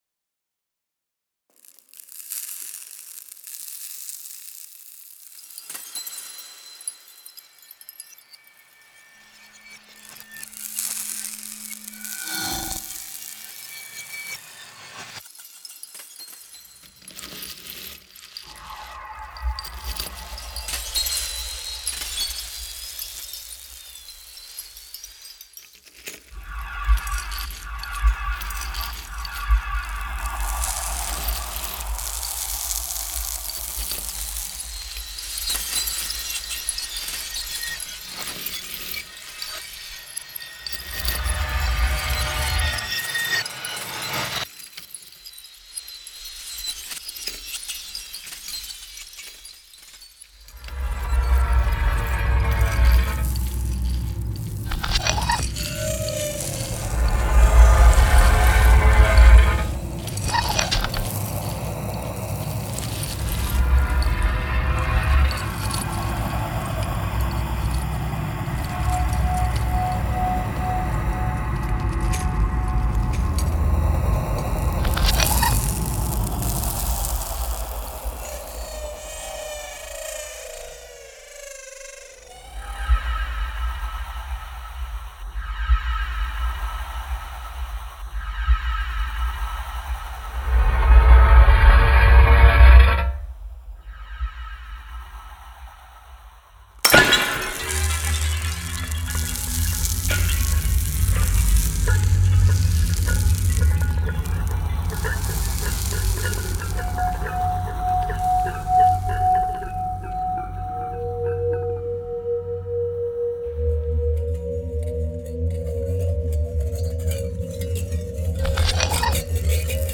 Et à l'occasion des 10 ans de la Volte, des textes des auteurs de la maison d'édition, lu par les auteurs eux-mêmes, ont été mixés par Phaune Radio et proposés à l'écoute.
Utopiales2014PhauneDamasio.mp3